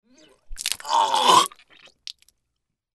Звуки рвоты, тошноты
Напрягается